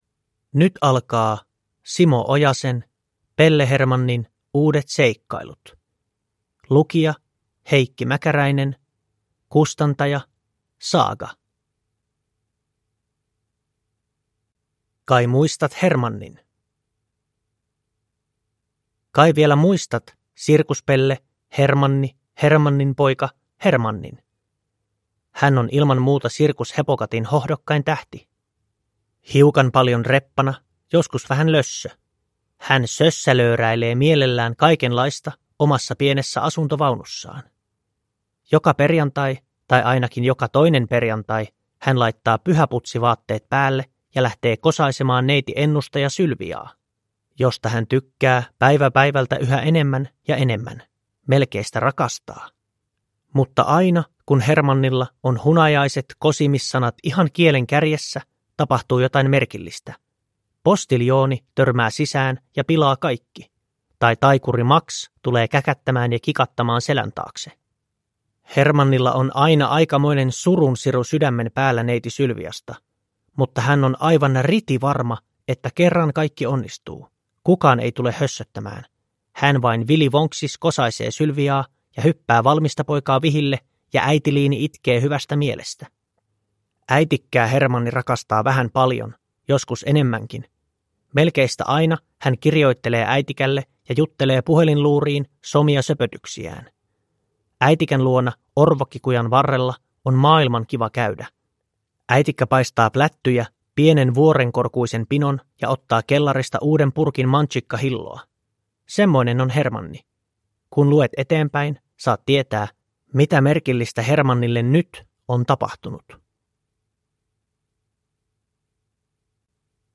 Pelle Hermannin uudet seikkailut – Ljudbok – Laddas ner